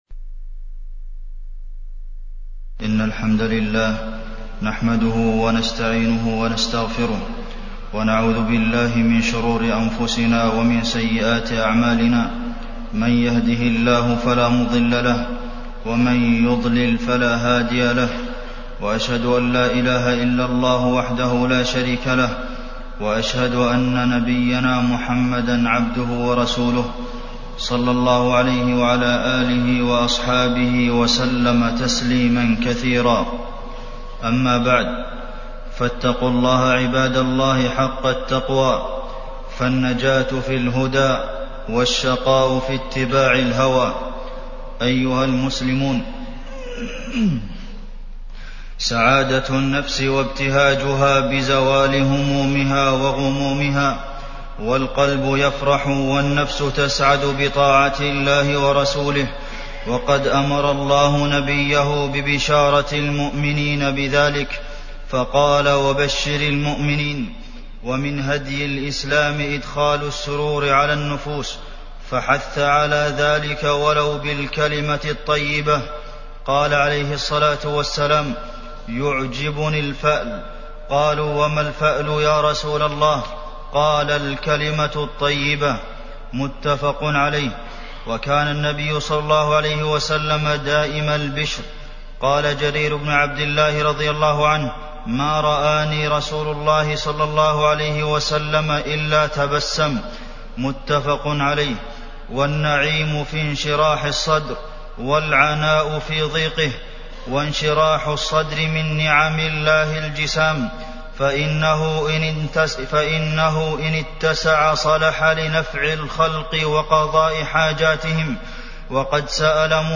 تاريخ النشر ٣ رجب ١٤٢٧ هـ المكان: المسجد النبوي الشيخ: فضيلة الشيخ د. عبدالمحسن بن محمد القاسم فضيلة الشيخ د. عبدالمحسن بن محمد القاسم أمراض القلوب